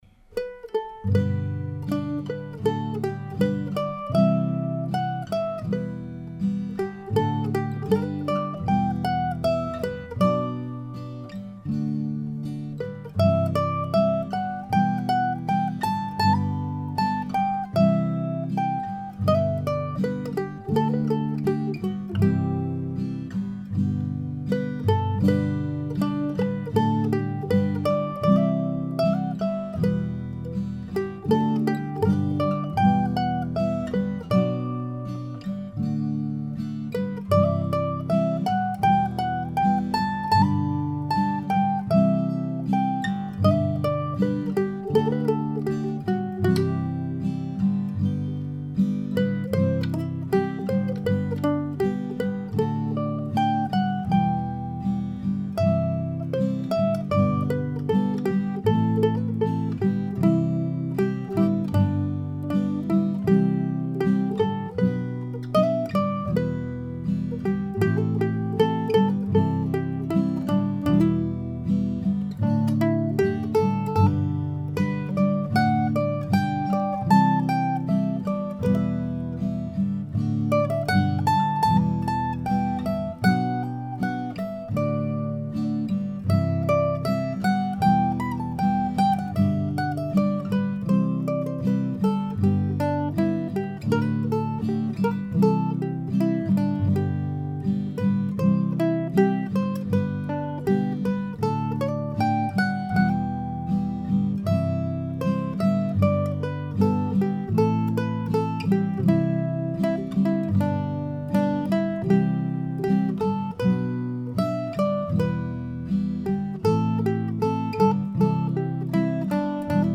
This particular tune is at least 10 years old and I found the recording on a CD of demos and such from an old multi-track recorder that I no longer own. It's meant to go pretty slow.